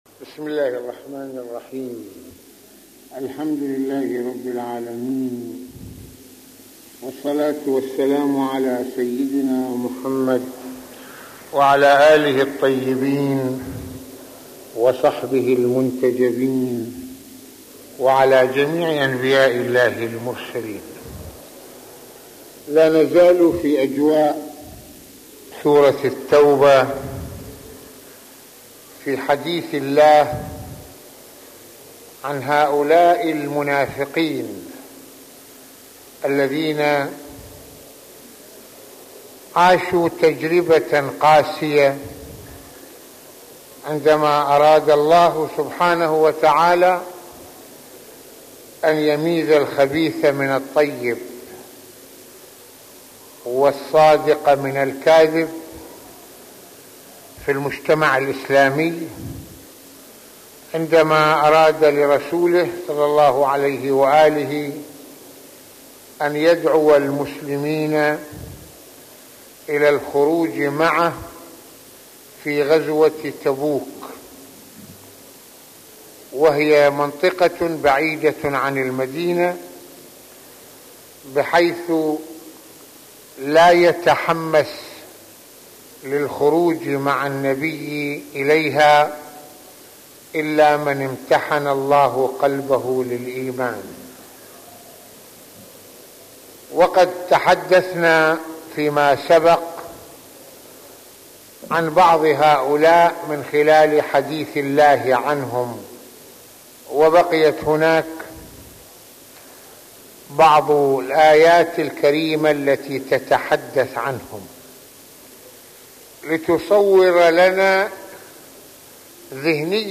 - يتحدث العلامة المرجع السيد محمد حسين فضل الله "رض" في هذه المحاضرة القرآنية عن الجهاد في سبيل الله بما يمثل من ضرورة لتأكيد التحرر من الانشداد للمظاهر الدنيوية وبلوغ العزة والكرامة والإلتفات إلى المسؤولية الملقاة على الإنسان كفرد وجماعة وعدم الخلود إلى الأرض والخنوع مشيرا إلى قصة الغار مع النبي الأكرم "ص" وما فيها من دروس